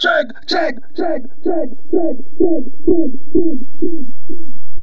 CHECK ECHO.wav